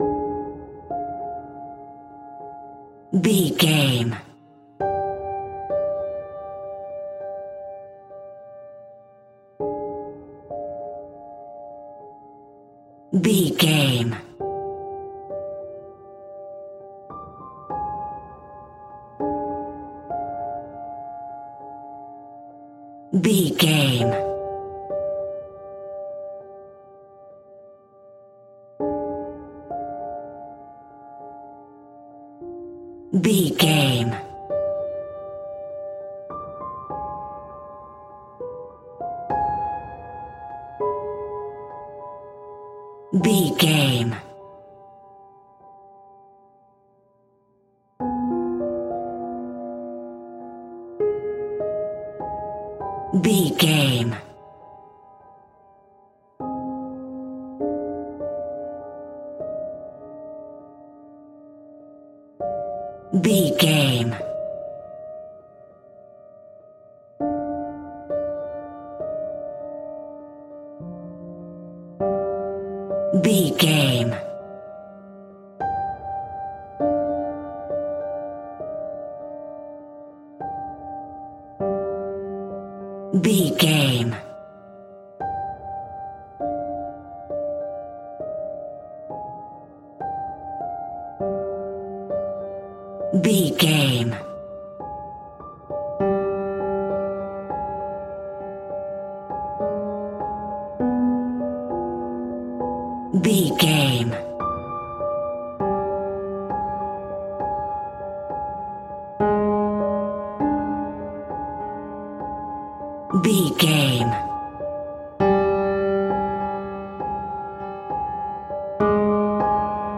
royalty free music
Aeolian/Minor
Slow
tension
ominous
haunting
eerie
horror music
horror piano